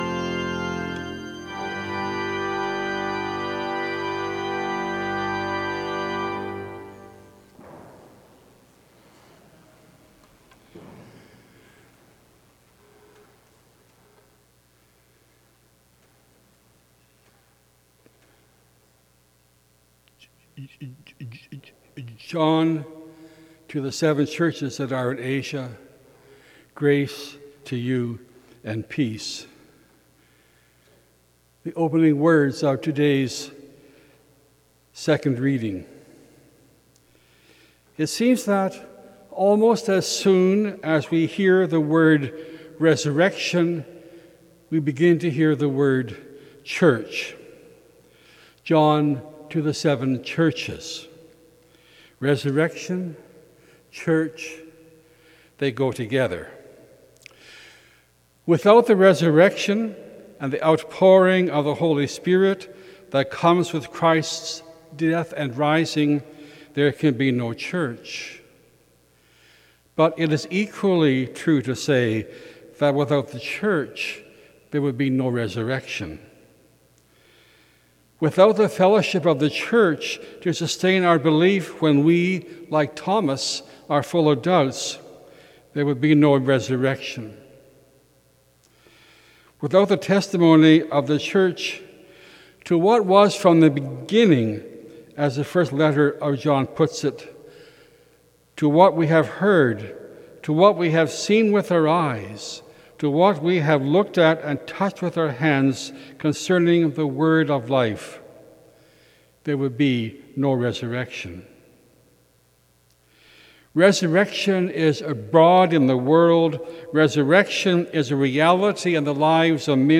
Sermon: 11.00 a.m. service